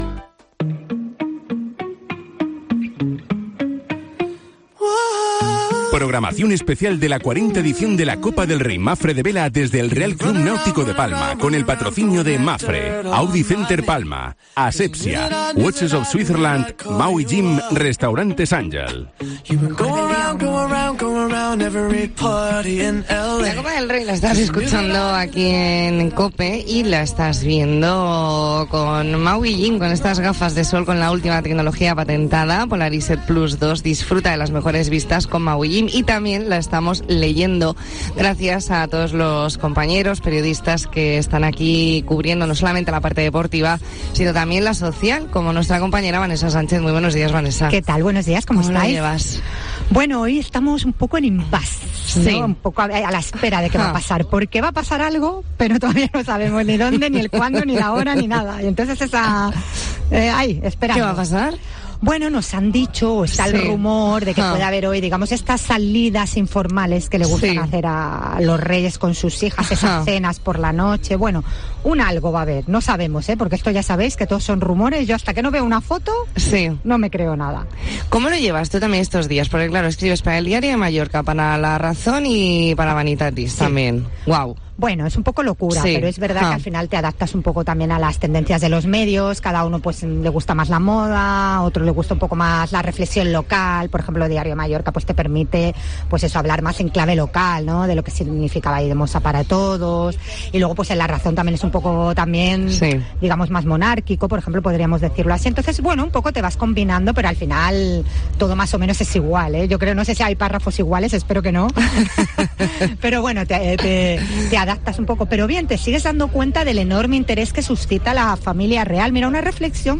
Especial La Mañana en COPE Más Mallorca desde el RCNP con motivo de la 40 Copa del Rey Mapfre